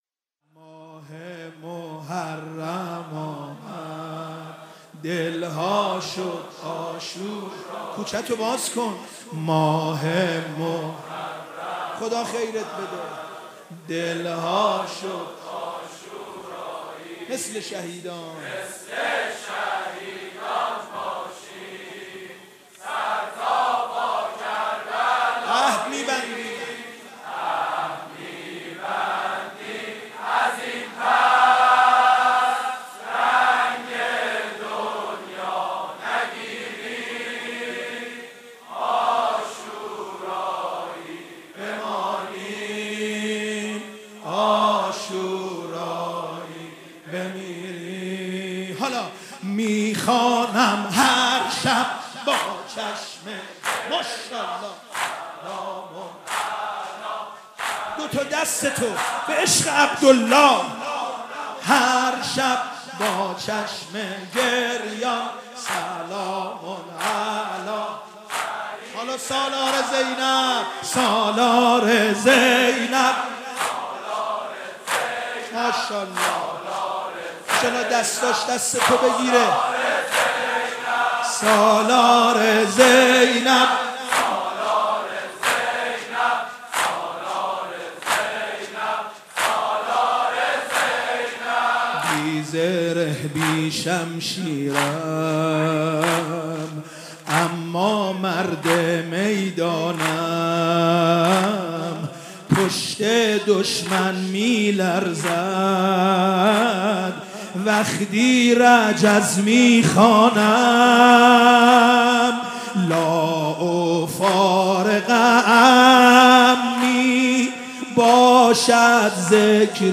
محرم 95
نوحه